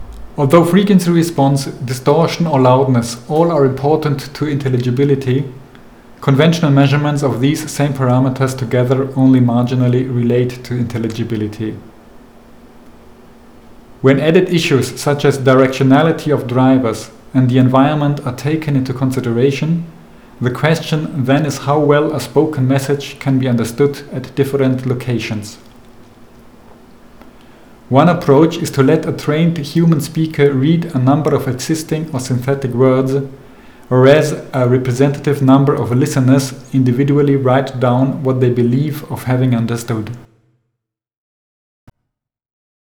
下面两段声音也直观展示了混响时间对清晰度的影响：
混响时间较小时.wav